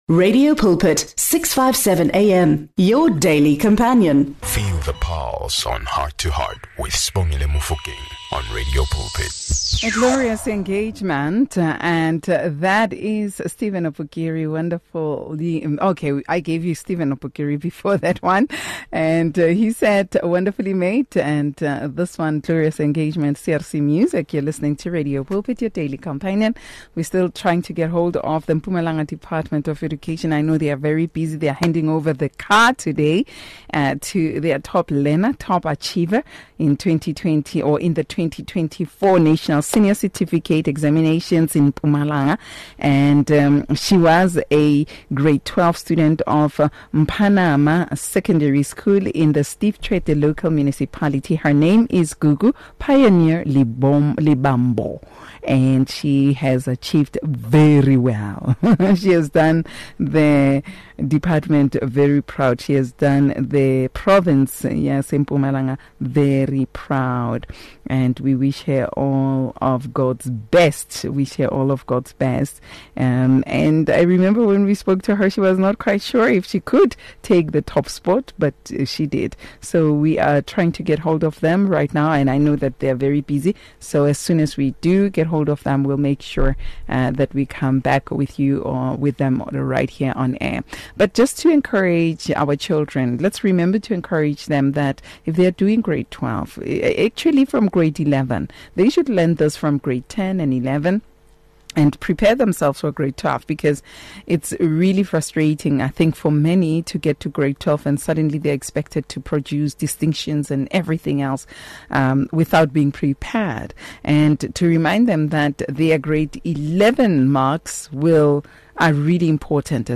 Heart to Heart is a magazine show on Radio Pulpit that brings you teachings, gospel music and advice.
To keep the content fresh, inspiring and from different perspectives, we have three presenters, each with their unique style.